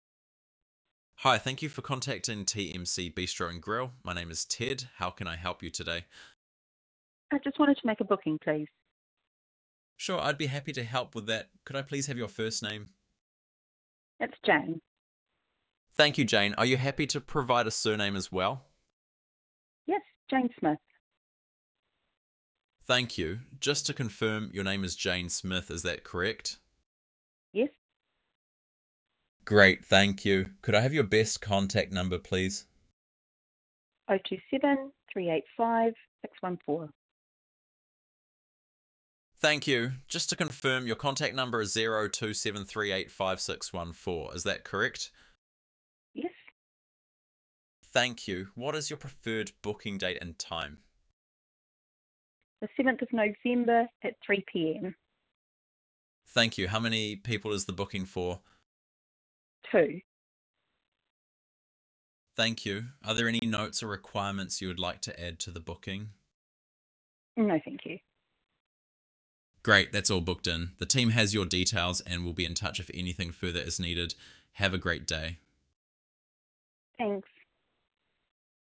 Natural, human-like dialogue with multilingual options.
Want to hear how natural it sounds? Take a moment to listen to our AI agent in action.